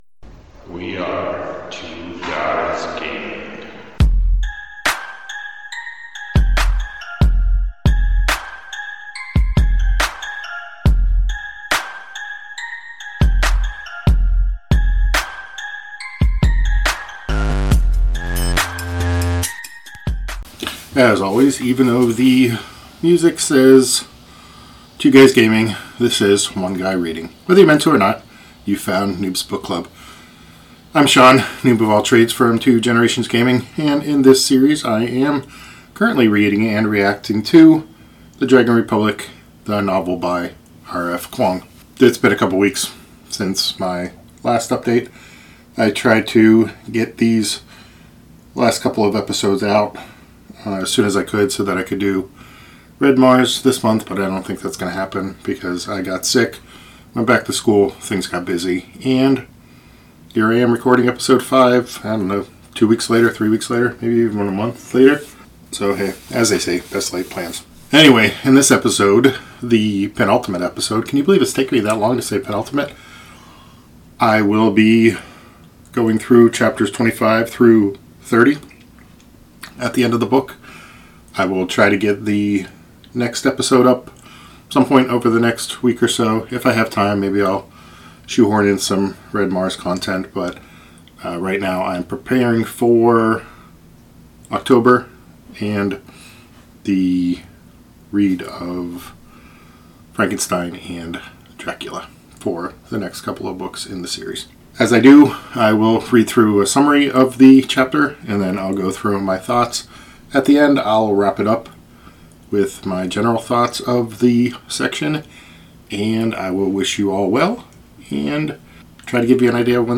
reads and reacts to chapters 25 - 30 in The Dragon Republic Episode 5